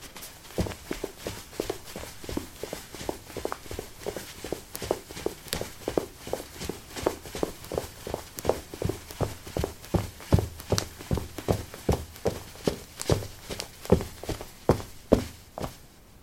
描述：这是一种模拟跑步者的音频效果
标签： 运行 男子 运行 步骤
声道立体声